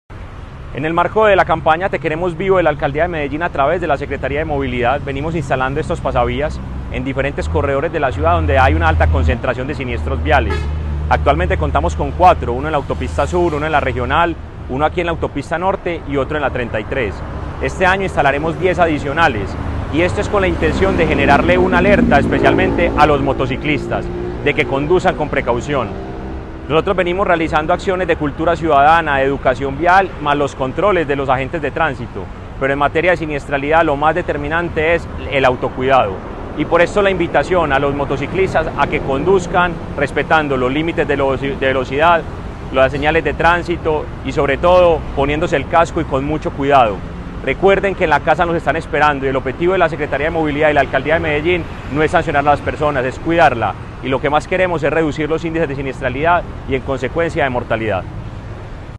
Audio Palabras de Mateo González Benítez, secretario de Movilidad